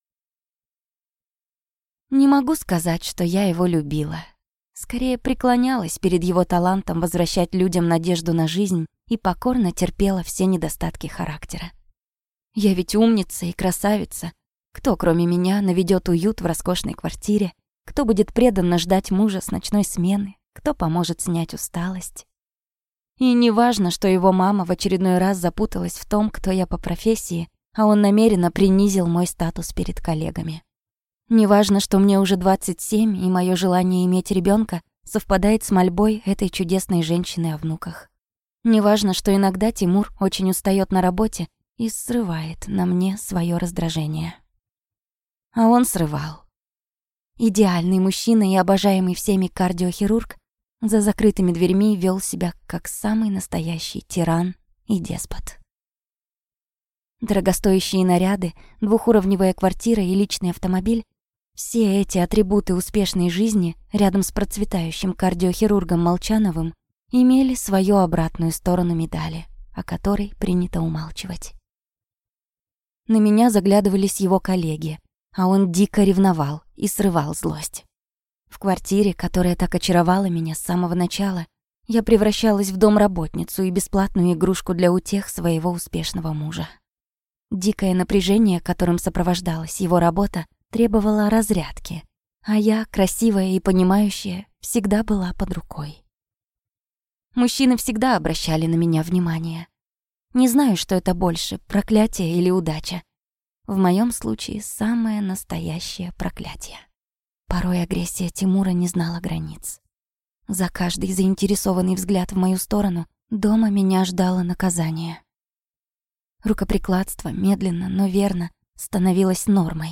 Аудиокнига Во власти тирана | Библиотека аудиокниг